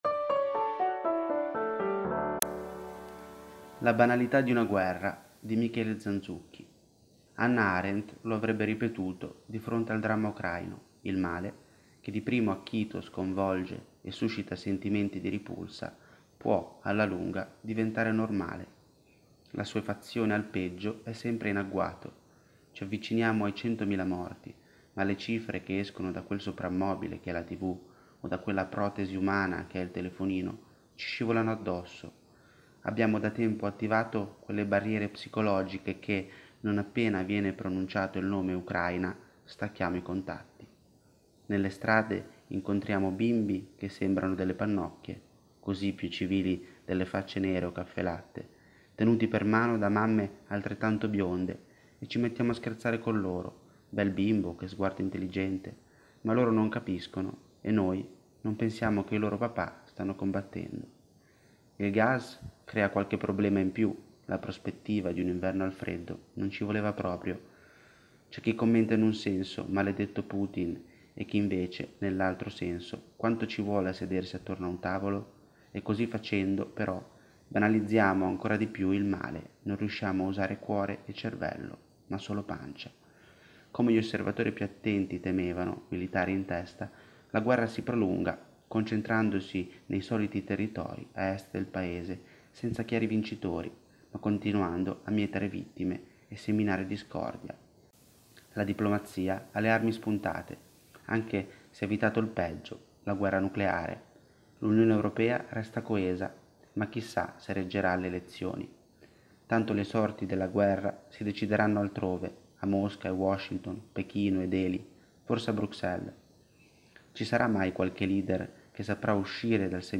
Al microfono, i nostri redattori e nostri collaboratori.